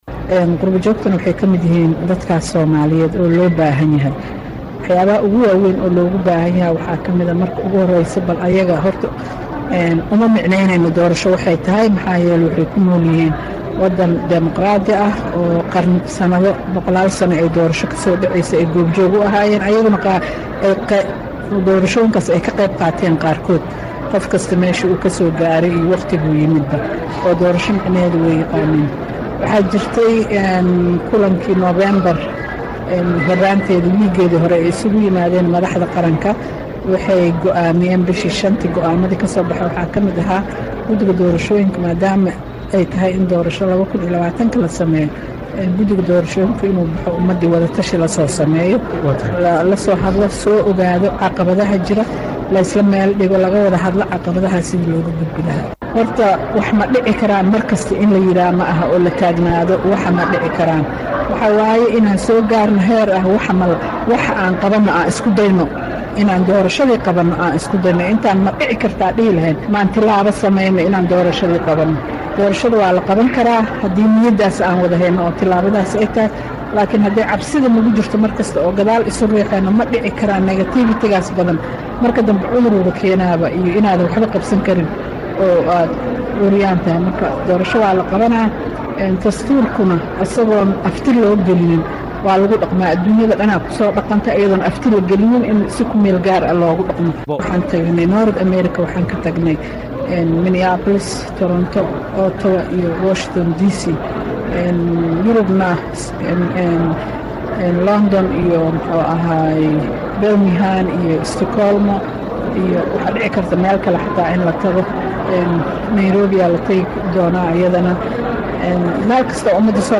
Xaliimo Ismaaciil Ibraahim “Xaliimo Yarey” Guddoomiyaha Guddiga Doorashooyinka ee Madaxa Banaan ee Soomaaliya ayaa sheegtay iyada oo lahadleysay VOA-da in ay muhiim tahay kulamada Wadatashiga ee lala sameynayo Qurba Joogta Soomaaliyeed.